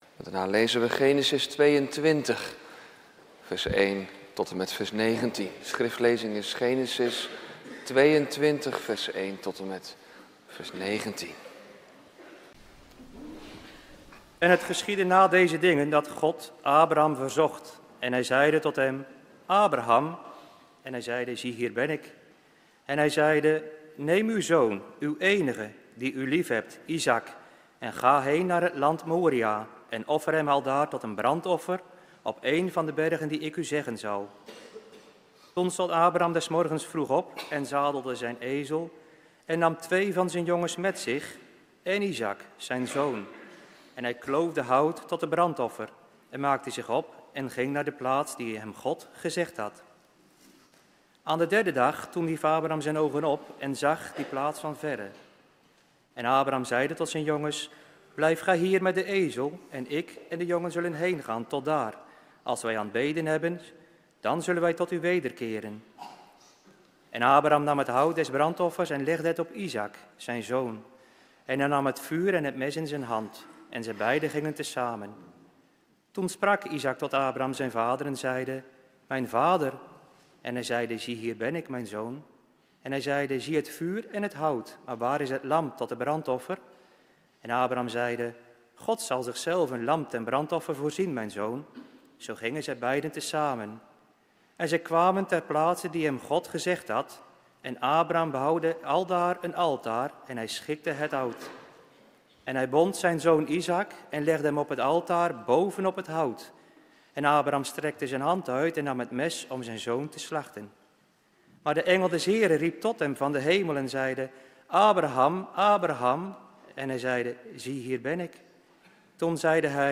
Twee bergbeklimmers prekenserie ‘tweetallen in het OT’, preek 4